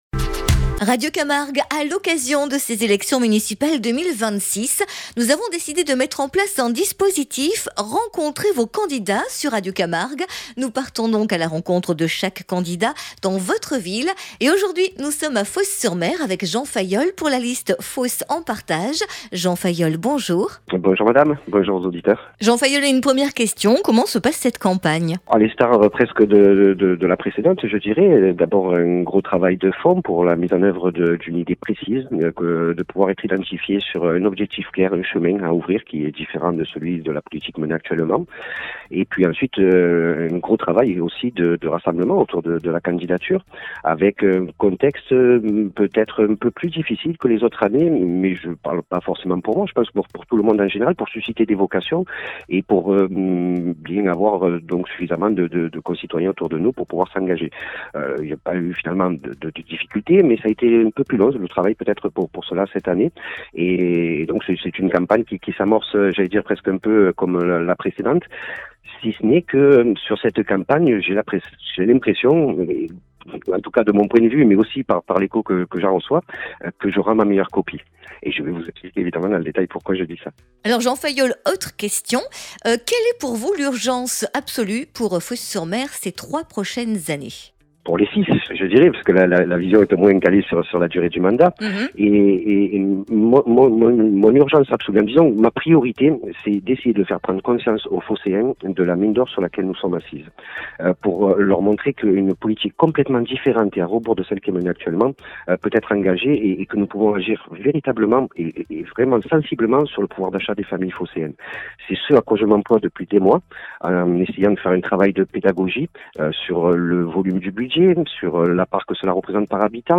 Municipales 2026 : entretien